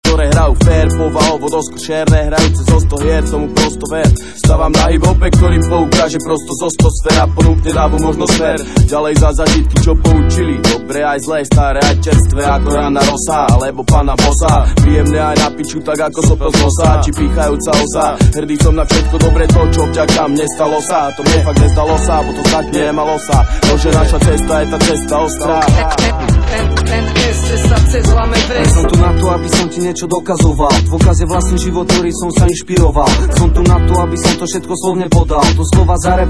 4 Styl: Hip-Hop Rok